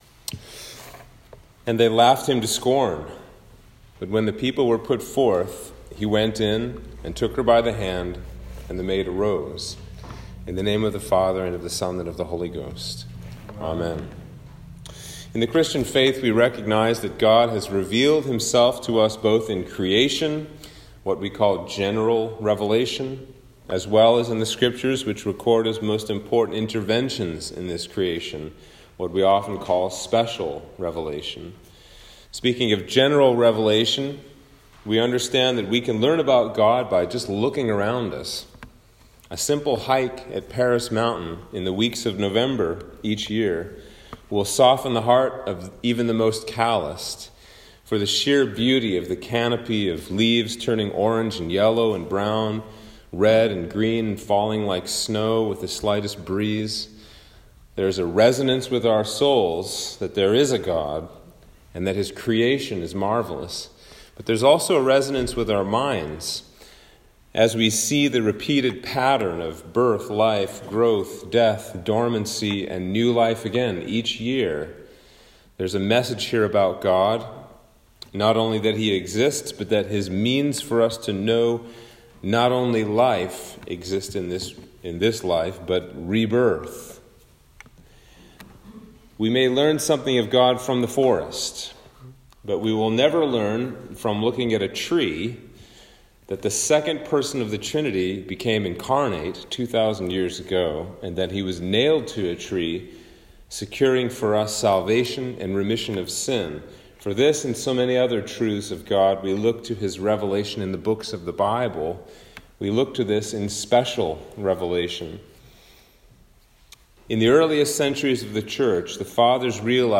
Sermon for Trinity 24 - 2021